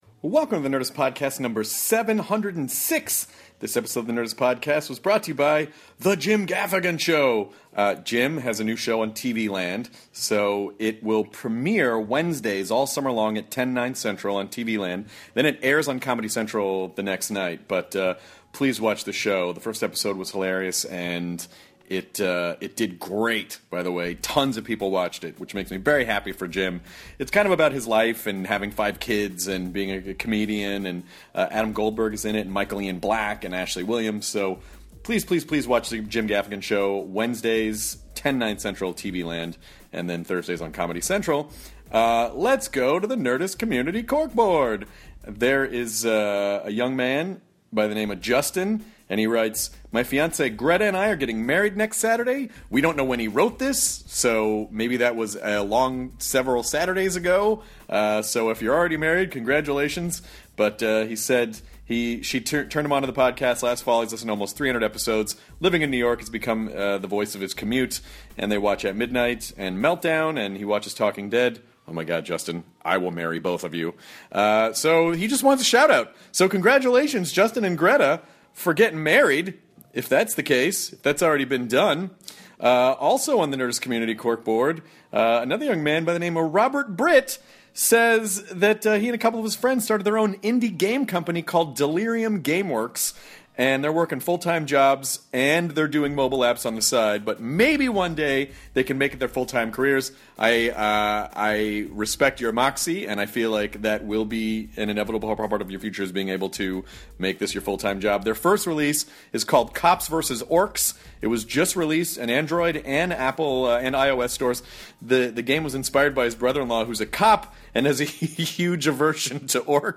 Sir Ian McKellen (Lord of the Rings, X-Men) chats with Chris about why people are so enamored with Sherlock Holmes, what Sherlock is like in his new movie Mr. Holmes and how being a celebrity can change a person. They also talk about his choice to be honest about his sexuality, being comfortable with himself and what advice he would give to other people!